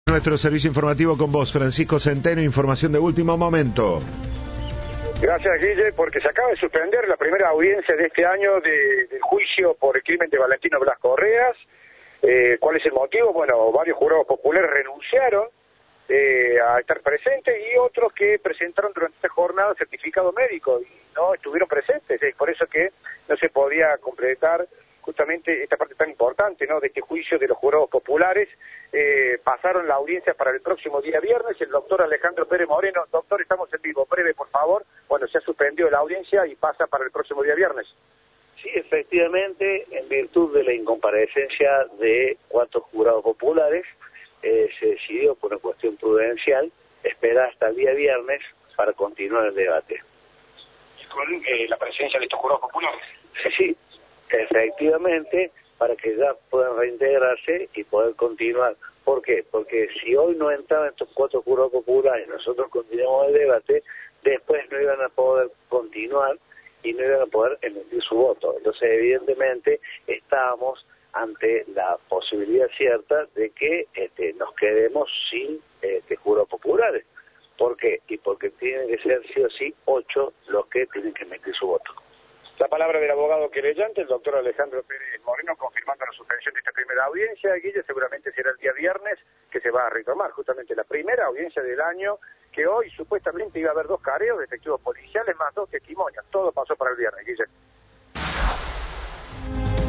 Informe de